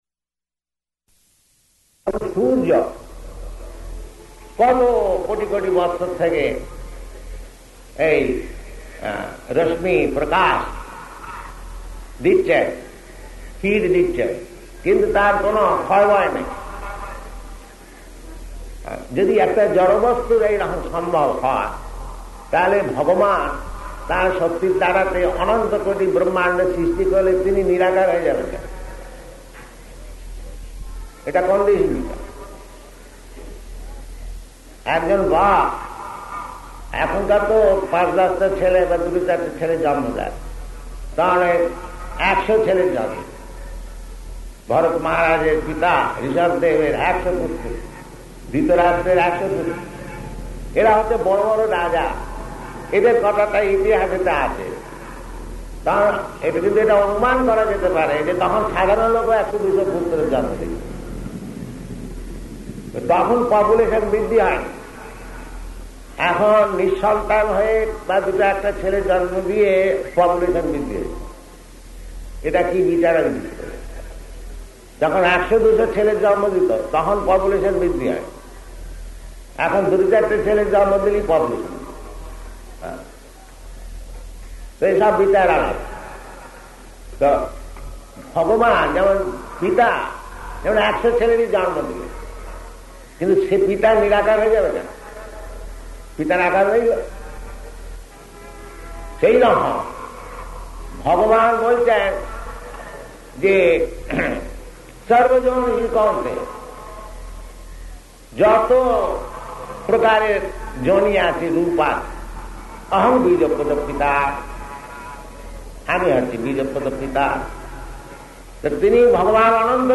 Lecture--Bengali
Type: Lectures and Addresses
Location: Calcutta